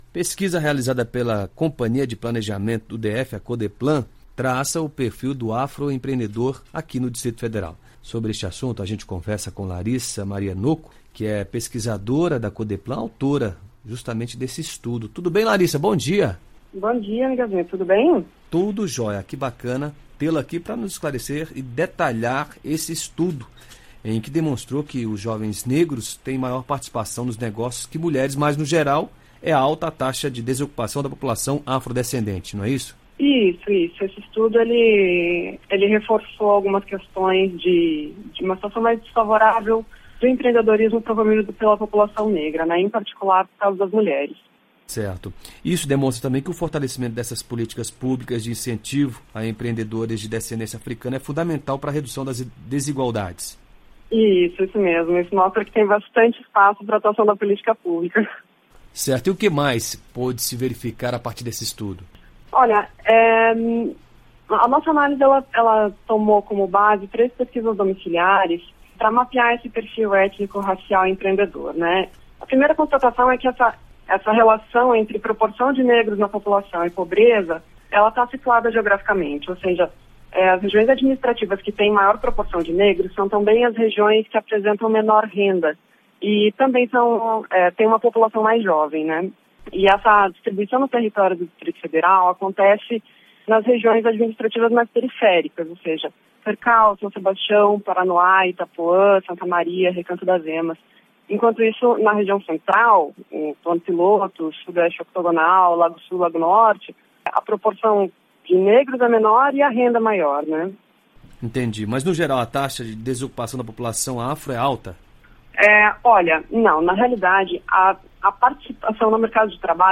Entrevista: Conheça a pesquisa sobre os afroempreendedores do DF